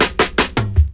FILL 9    -L.wav